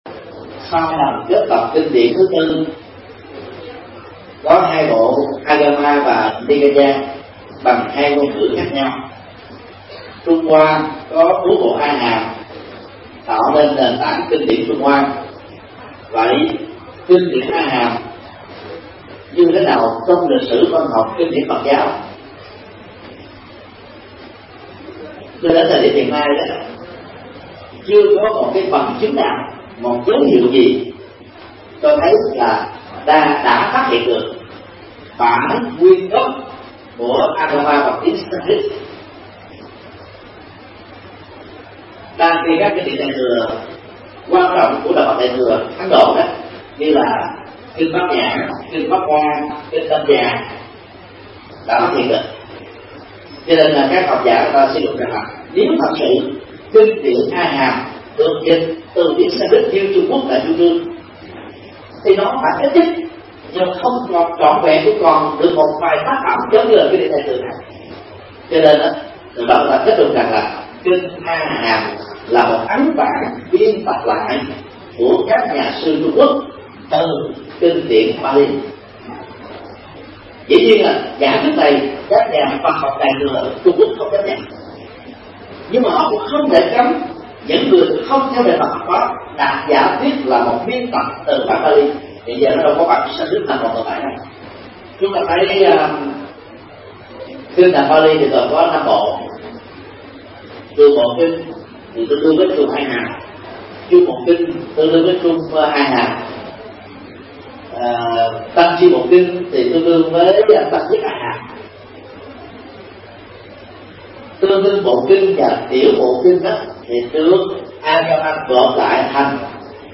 Vấn đáp: Kinh A Hàm – Mp3 Thầy Thích Nhật Từ Thuyết Giảng
Vấn đáp: Kinh A Hàm – Thích Nhật Từ